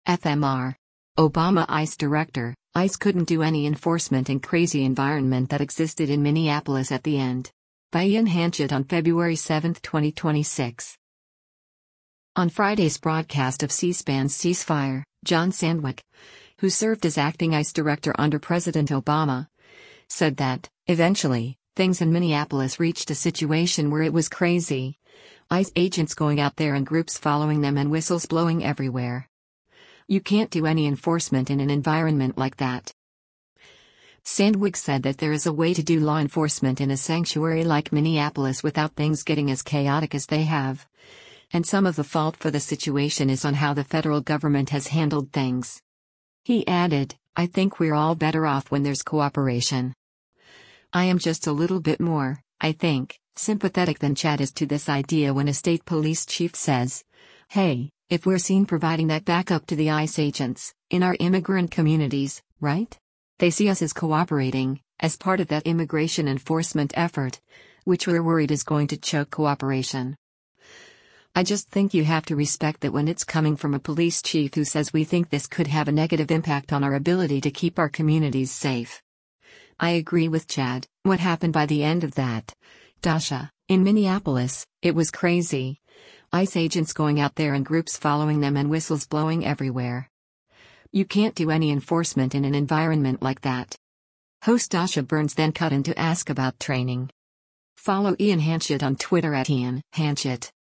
On Friday’s broadcast of C-SPAN’s “Ceasefire,” John Sandweg, who served as acting ICE Director under President Obama, said that, eventually, things in Minneapolis reached a situation where “it was crazy, ICE agents going out there and groups following them and whistles blowing everywhere. You can’t do any enforcement in an environment like that.”